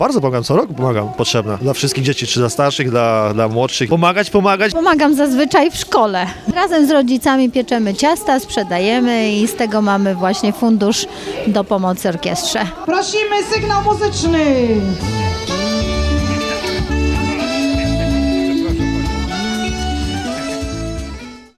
orkiestra sonda.mp3